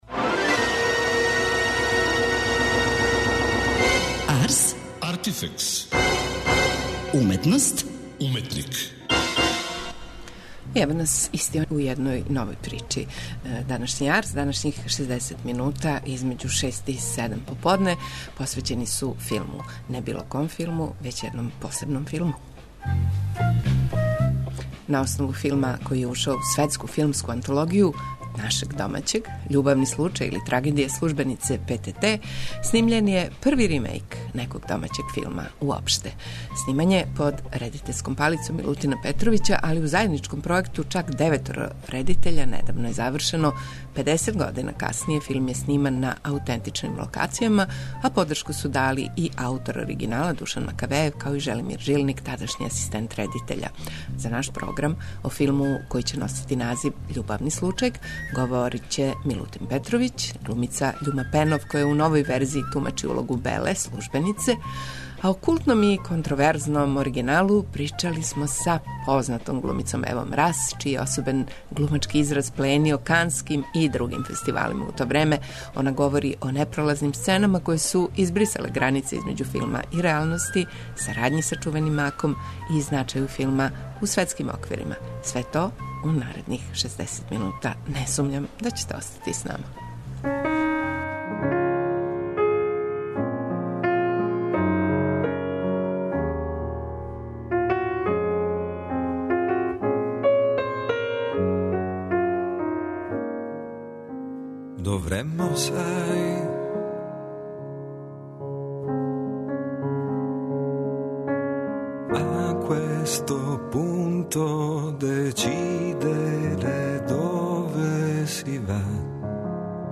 А о култном и контроверзном оригиналу причали смо са познатом глумицом Евом Рас, чији је особен глумачки израз пленио Канским и другим фестивалима. Рас говори о непролазним сценама које су избрисале границе између филма и реалности, сарадњи са чувеним ''Маком'' и значају филма у светским оквирима.